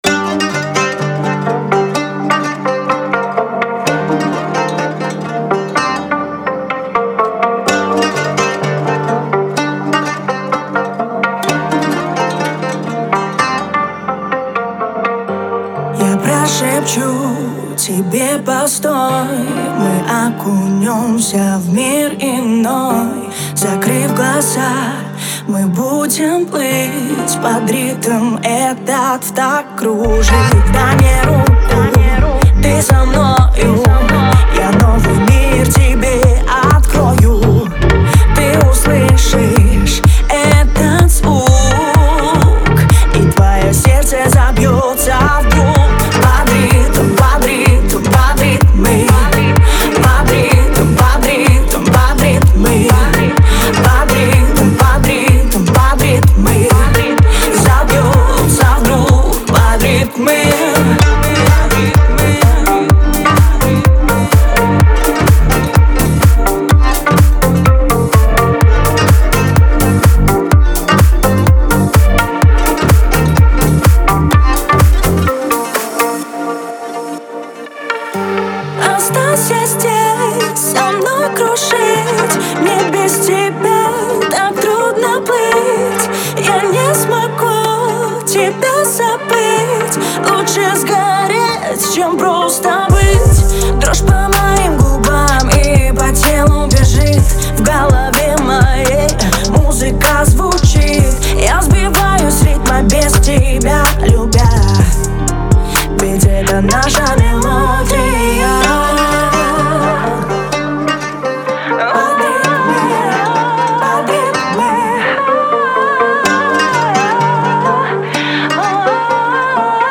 это зажигательный трек в жанре хип-хоп